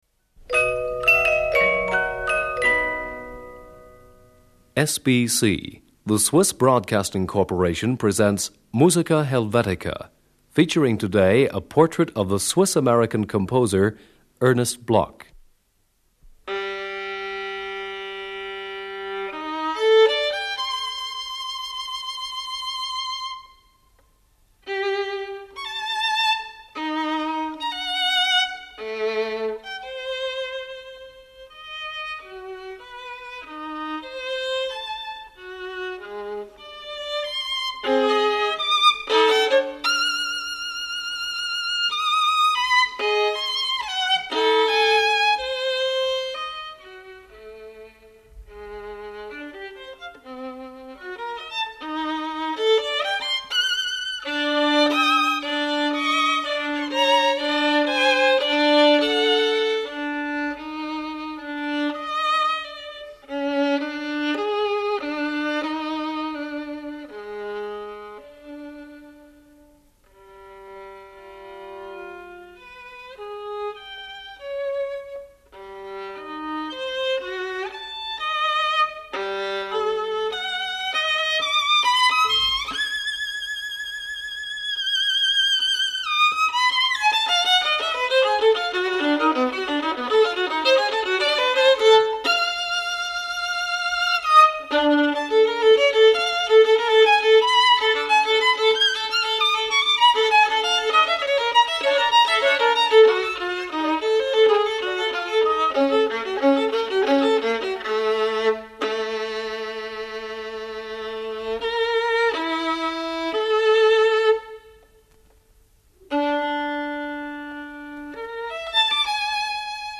violin. 2.
Symphonic Fresco (excerpt).
for Flute and Orchestra.
flute.